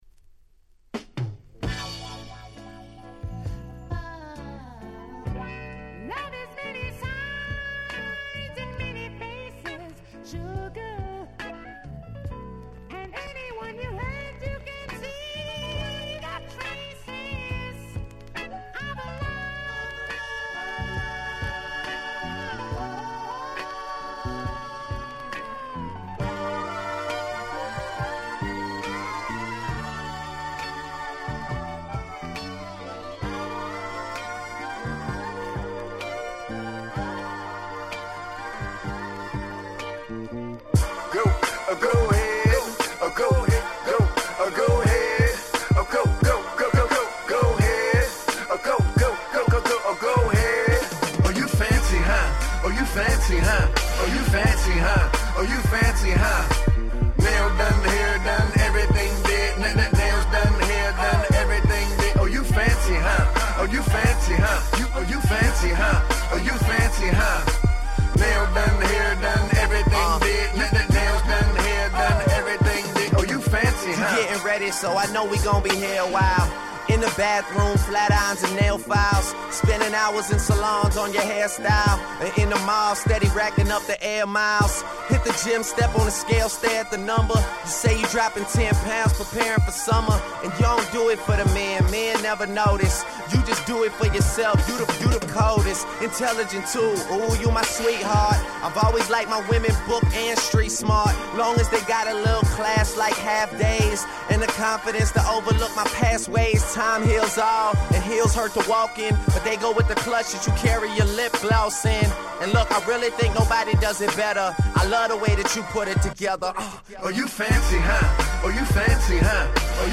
10' Super Hit Hip Hop !!